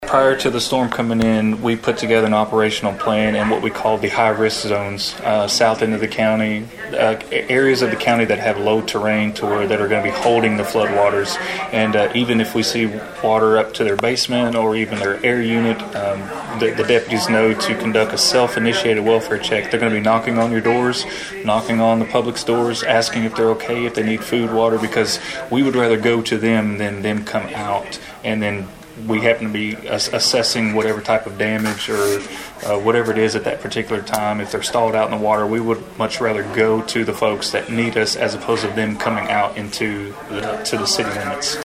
More than a dozen of Trigg County’s lead officials gathered Monday morning at the Emergency Operations Center on Jefferson Street, and all of them had the same message to send to the community.